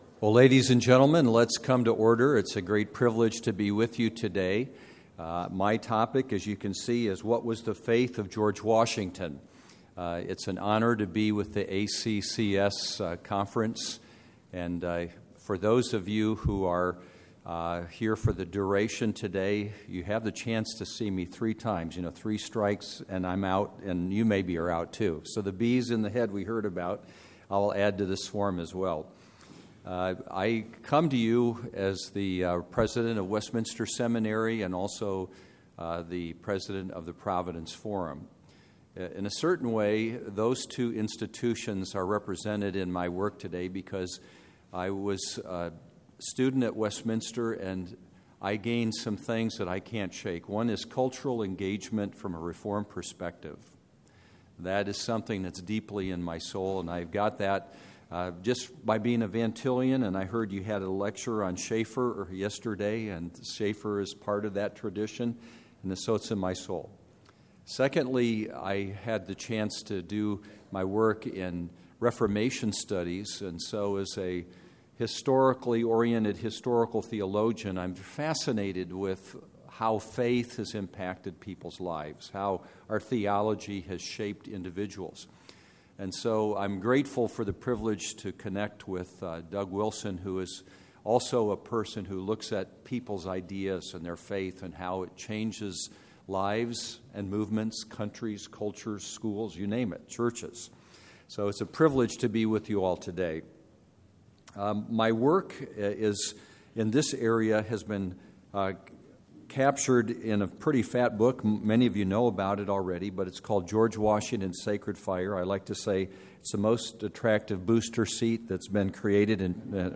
2012 Workshop Talk | 1:03:24 | History
The Association of Classical & Christian Schools presents Repairing the Ruins, the ACCS annual conference, copyright ACCS.